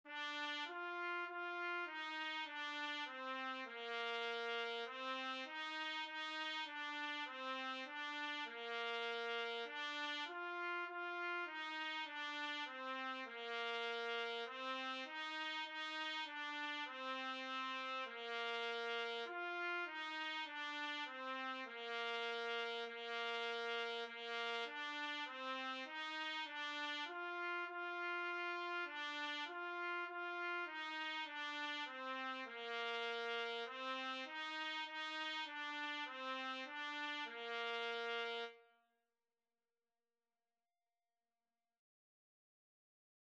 4/4 (View more 4/4 Music)
Bb4-F5
Trumpet  (View more Beginners Trumpet Music)
Classical (View more Classical Trumpet Music)